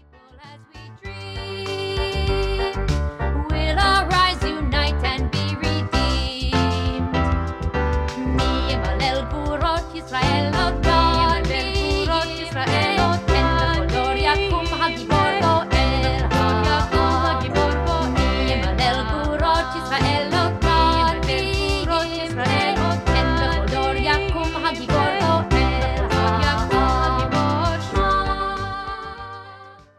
(Folk)